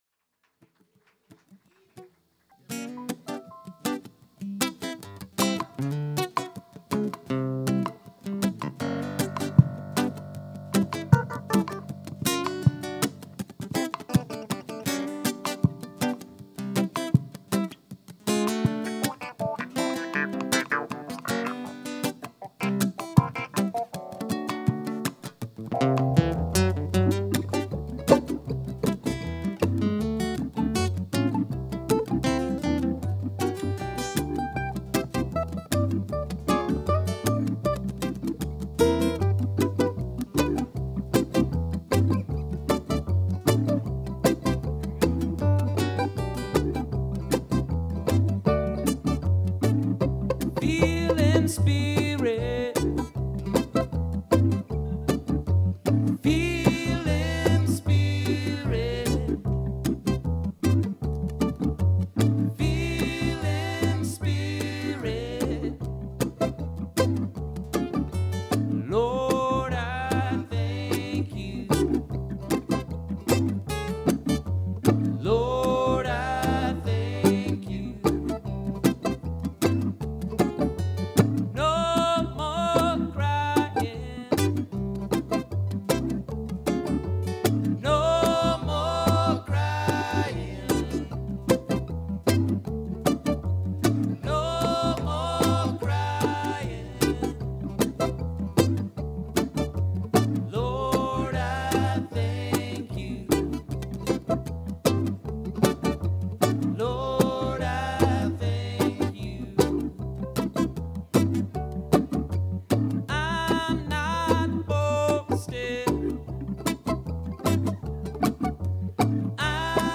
This is the first of 3 sets.
Alligator Alley Oakland Park, Florida
Banjo,Fiddle,Mandolin,Electric Banjo, Vocals
Organ, Clavinet, Piano, Bass, Melodica, Vocals
Guitar,Mandolin,Vocals